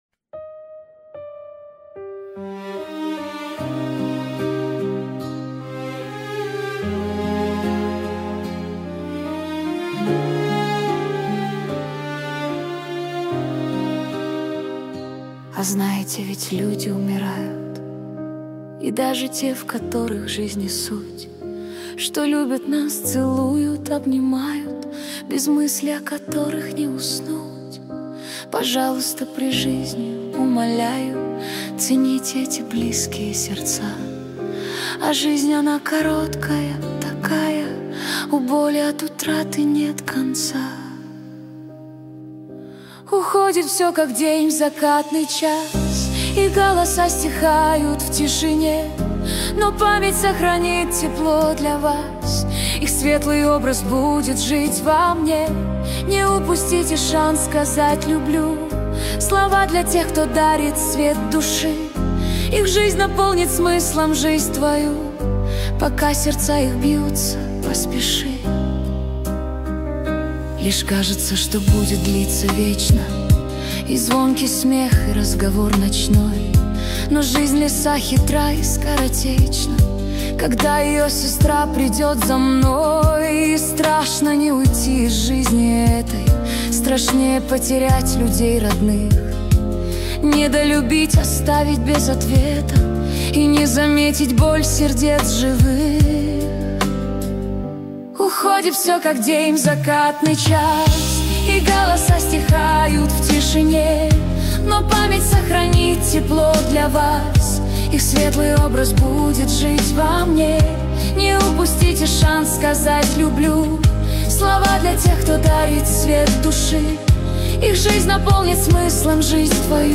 13 декабрь 2025 Русская AI музыка 73 прослушиваний